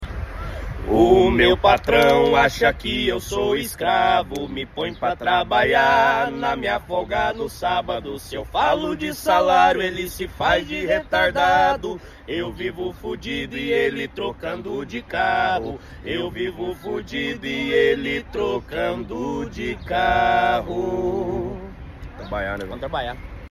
canta com tanto sentimento que chegou a se emocionar